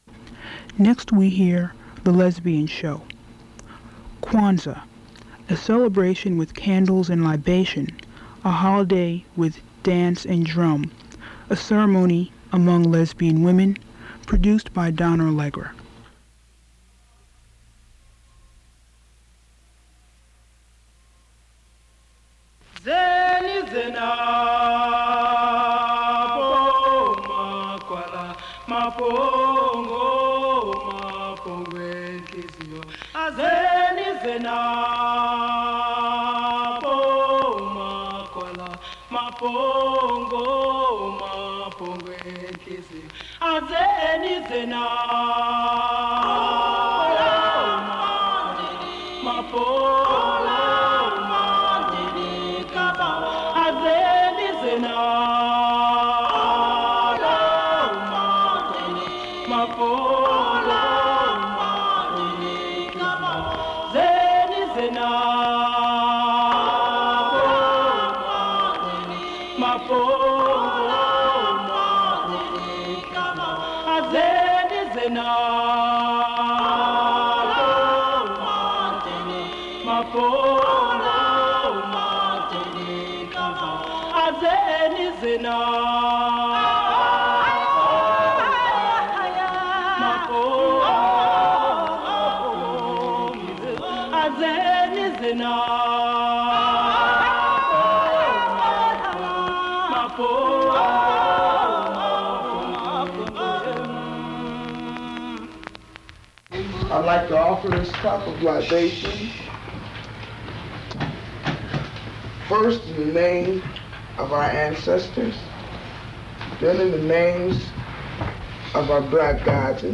Download File Download File Title The Lesbian Show - Kwanza Subject Kwanzaa Description Recordings of a group discussion around the 7 principles of Kwanzaa, particularly in regards to community and faith, interspersed with singing of Kwanzaa songs.